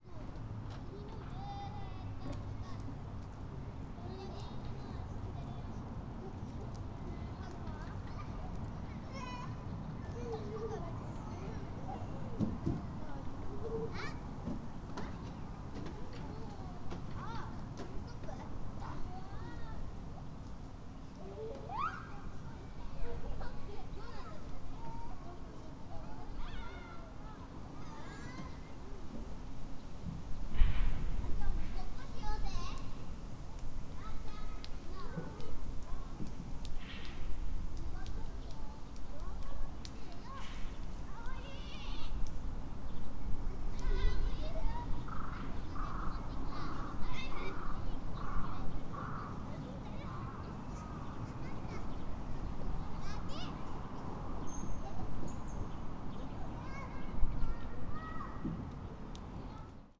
Fukushima Soundscape: Shinhama Park